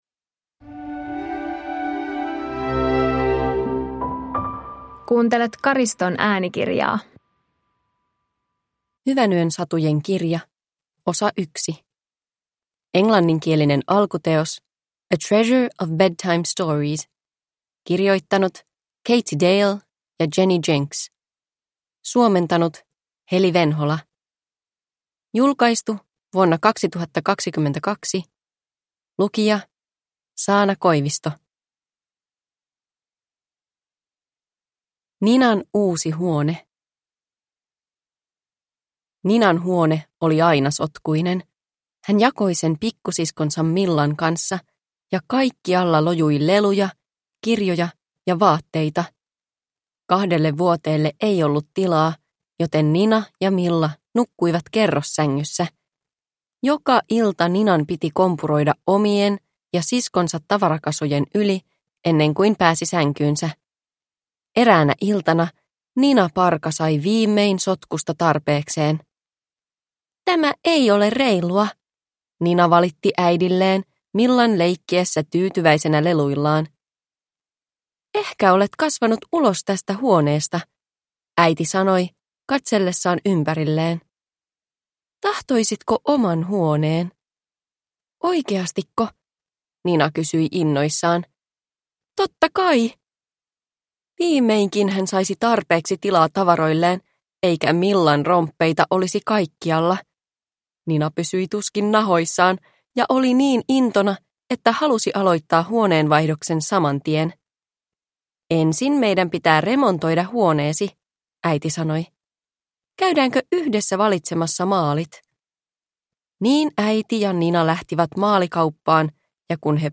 Hyvänyön satujen kirja 1 – Ljudbok – Laddas ner